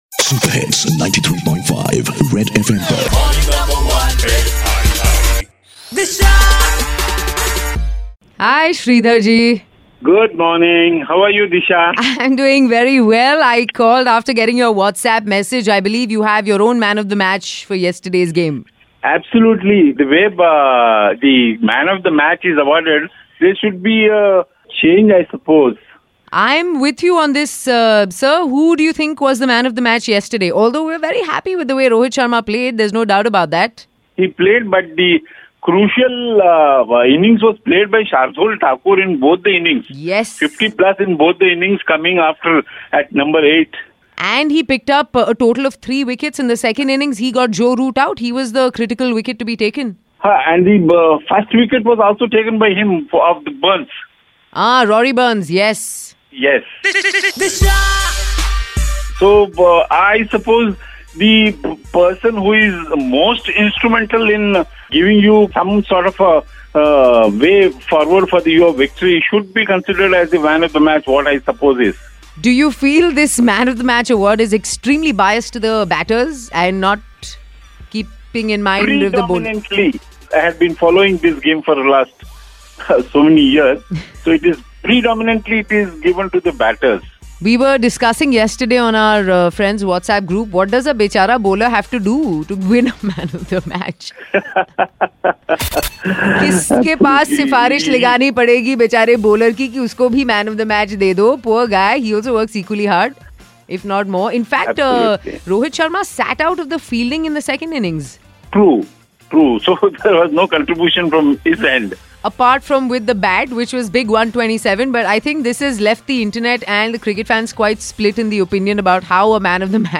Tune in to the heated debate on MN1!